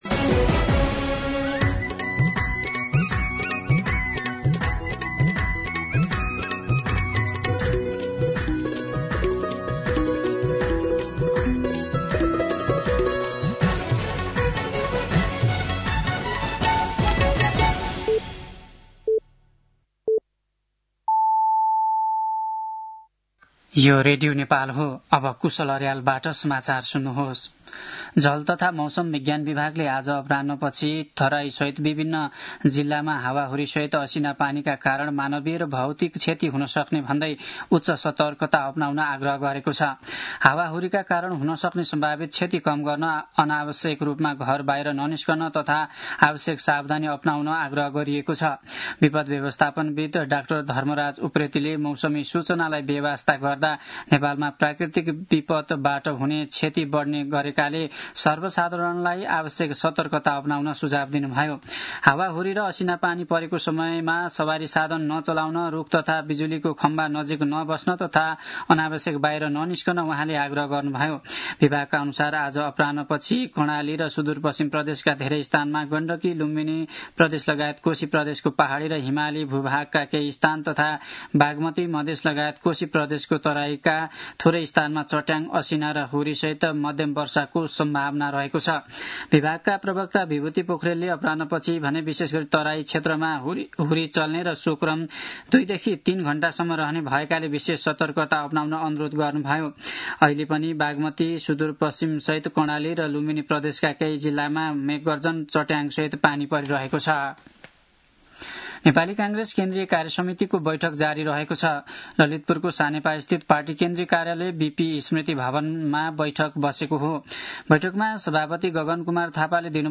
दिउँसो ४ बजेको नेपाली समाचार : ६ चैत , २०८२